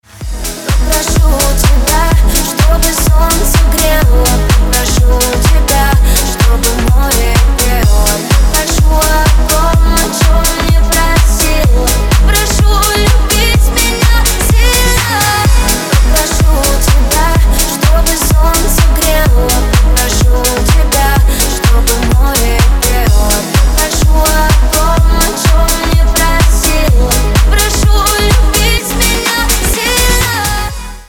Remix 2025